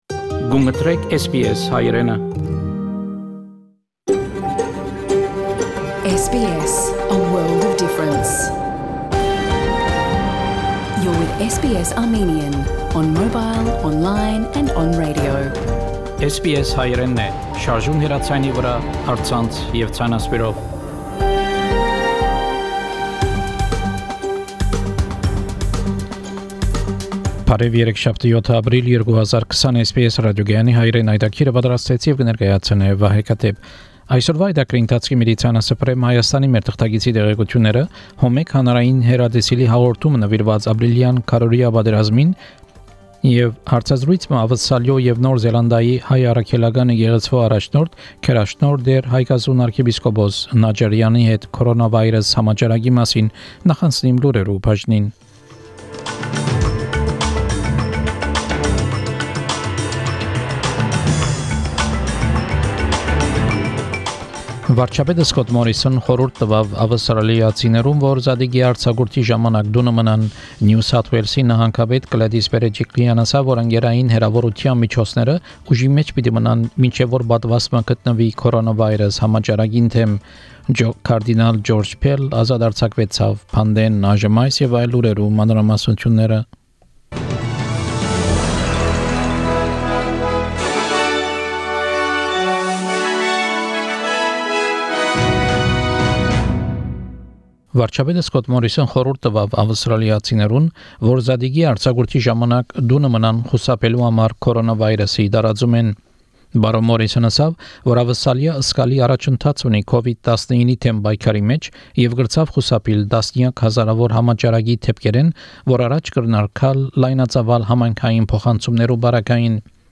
SBS Armenian news bulletin - 7 April 2020